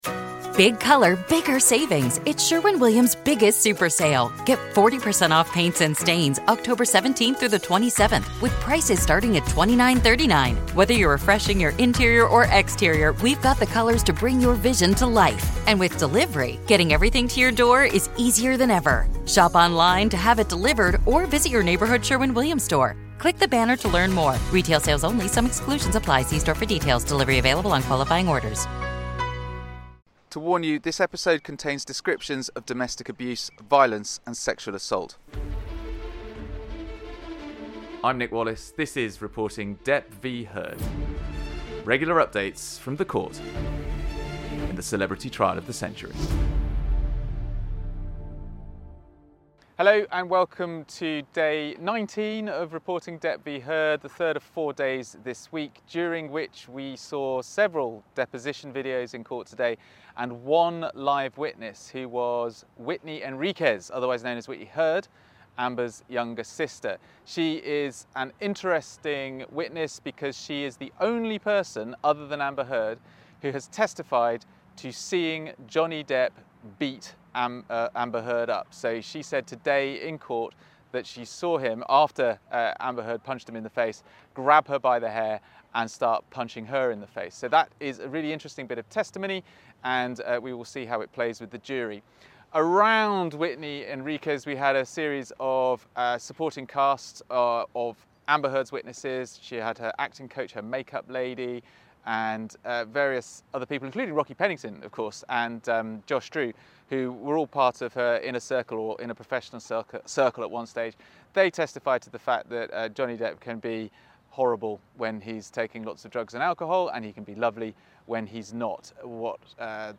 This episode features two park bench interviews; one with a Johnny Depp supporter (possibly?) and another with one of the VERY few Amber Heard supporters, neither of whom have slept for at least 36 hours.